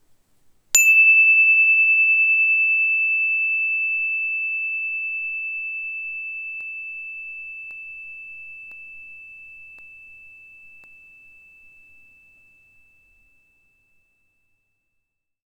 NINO Percussion Energy Chime (NINO579M)
These NINO® instruments have a very cutting “ping” sound. The free-floating bars create a very long sustain.